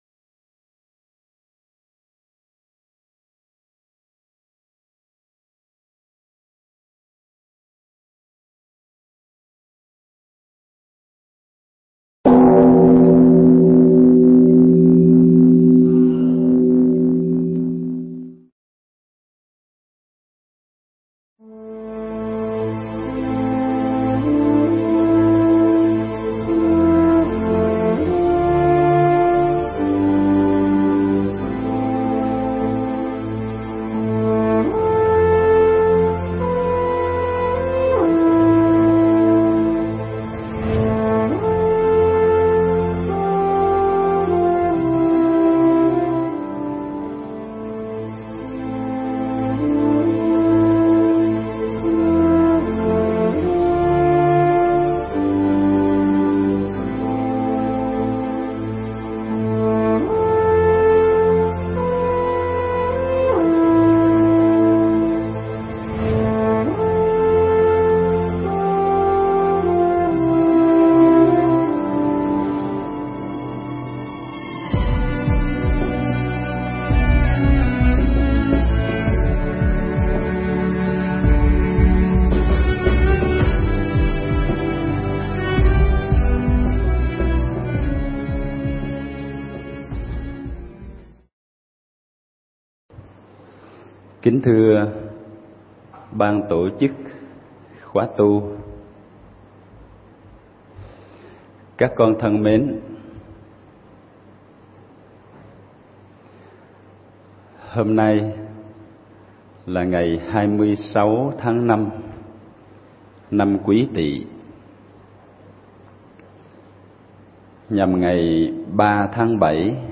Nghĩa nặng tình sâu – Thuyết pháp